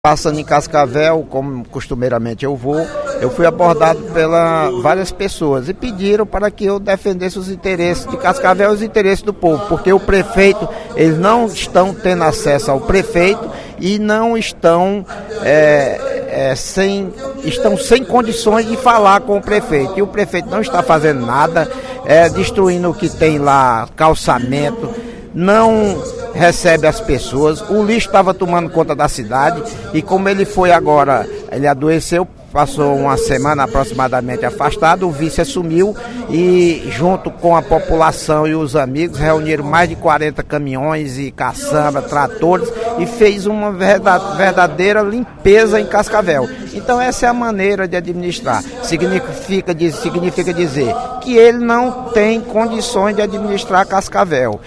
O deputado Idemar Citó (DEM) afirmou, durante a sessão plenária desta sexta-feira (24/02), que o prefeito de Cascavel, Décio Munhoz, está acabando com a cidade.